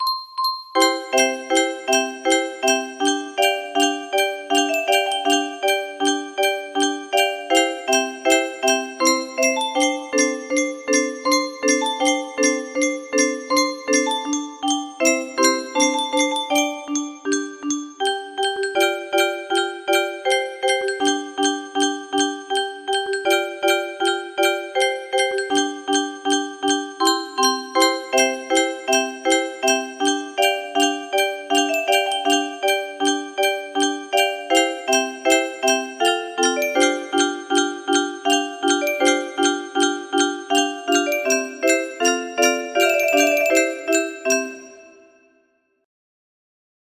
Minuet Boccherini music box melody